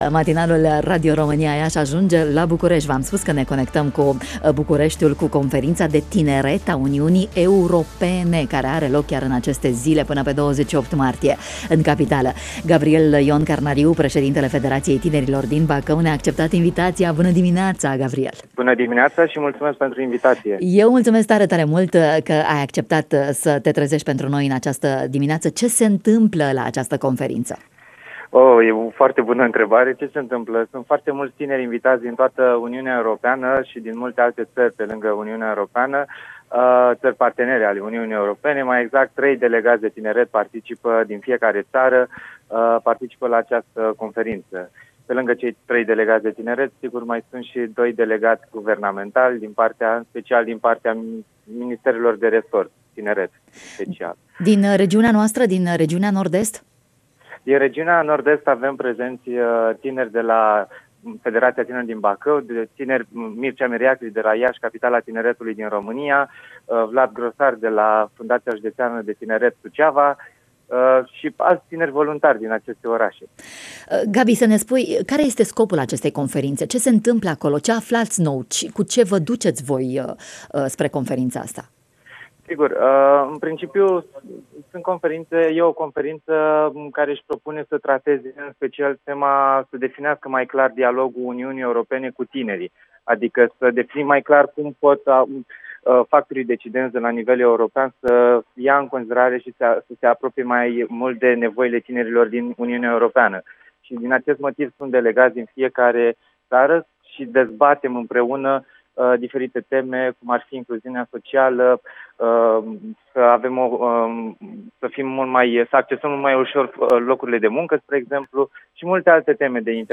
În direct, din capitală, în matinalul Radio România Iaşi